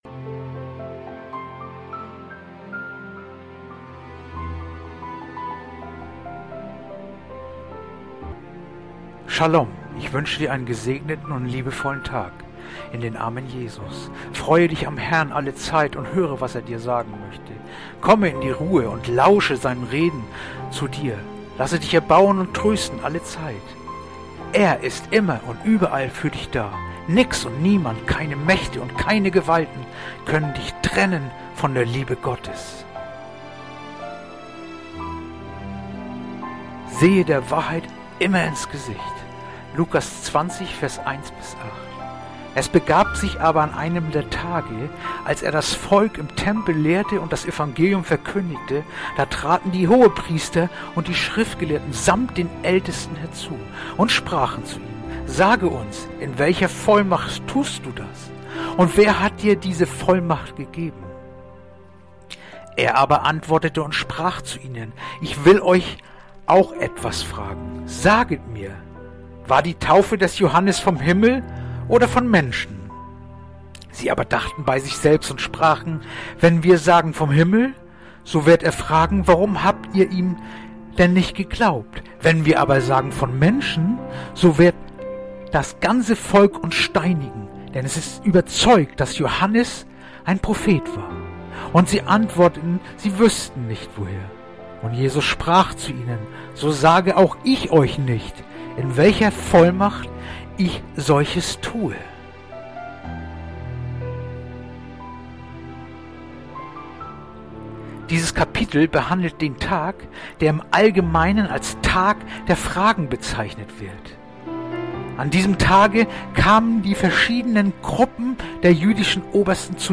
heutige akustische Andacht